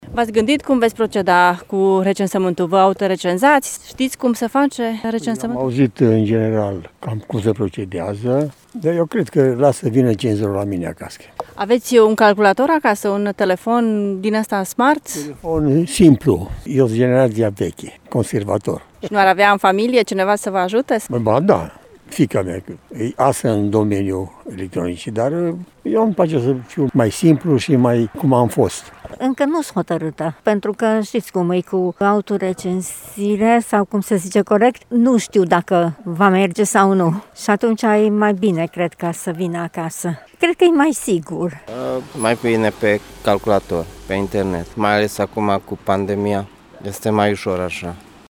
În funcție de cât sunt de obișnuiți cu tehnologia, târgumureșenii acceptă sau nu autorecenzarea: